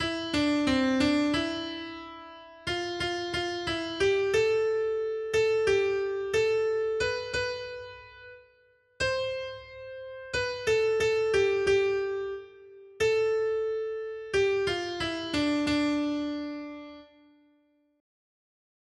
Noty Štítky, zpěvníky ol725.pdf responsoriální žalm Žaltář (Olejník) 725 Skrýt akordy R: Vytvořil jsi z nás našemu Bohu království a kněze. 1.